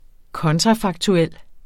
Udtale [ ˈkʌntʁɑ- ]